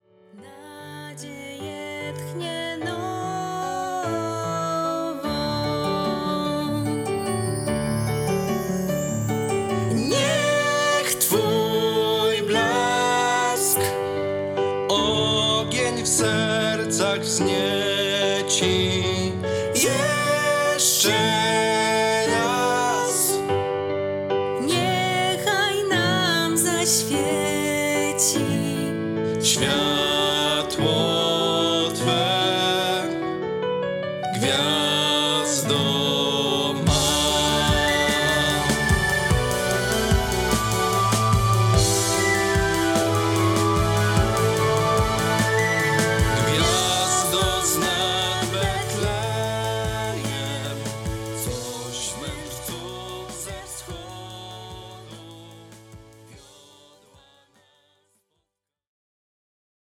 Oddajemy w Wasze ręce naszą autorską pastorałkę.